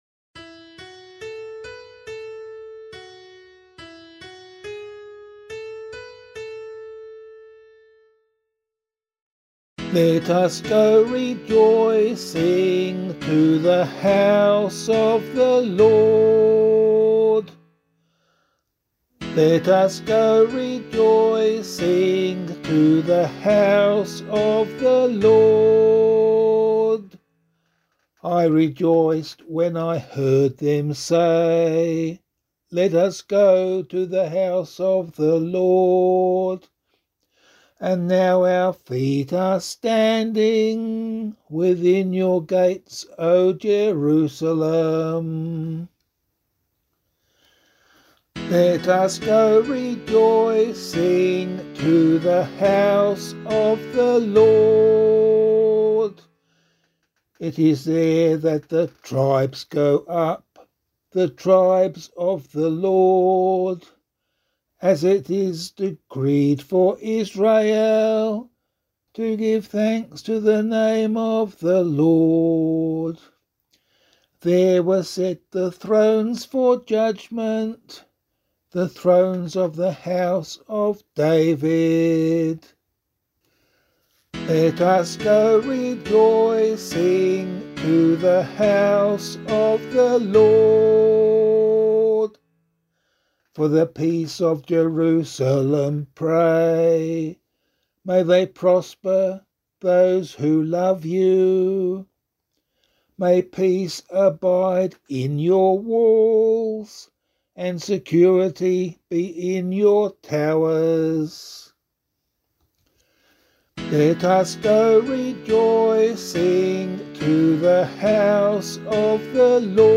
001 Advent 1 Psalm A [APC - LiturgyShare + Meinrad 8] - vocal.mp3